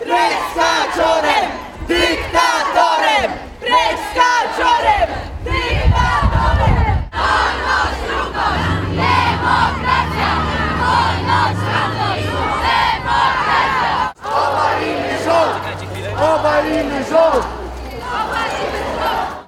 W środę (28.10) wieczorem kilkusetosobowy tłum przeszedł ulicami miasta z transparentami i okrzykami na ustach.